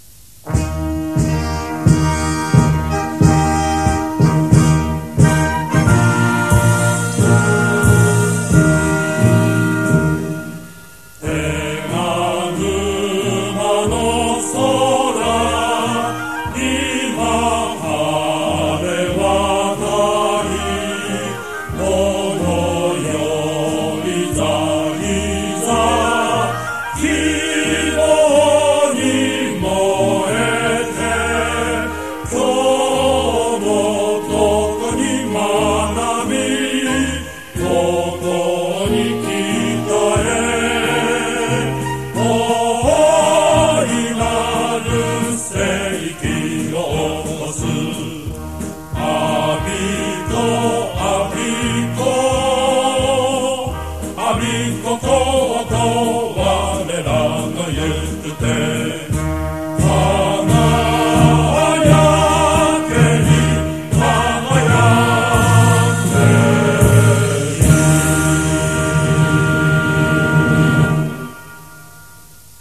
Music and the words in chorus (mp3, 1.1MB)